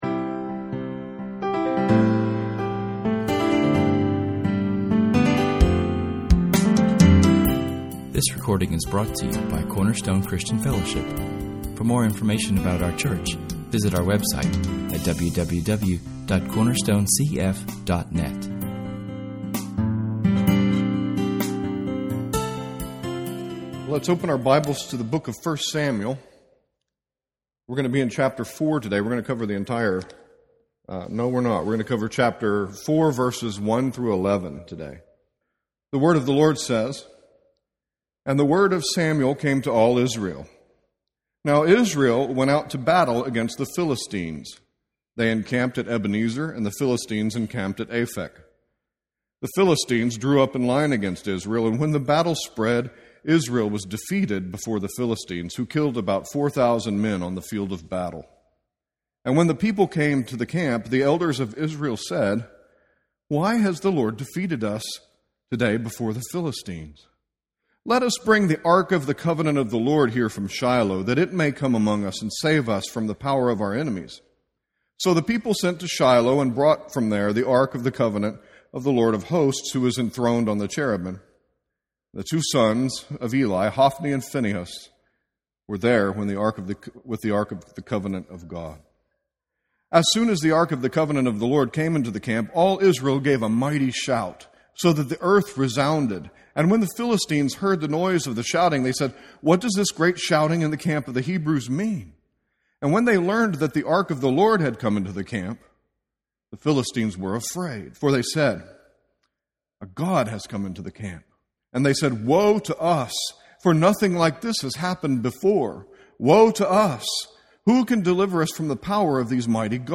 Here the story shifts from its focus on individual people and looks at the nation as a whole. In this sermon we meet the Philistines, the Ark and learn how they will become major characters in the story.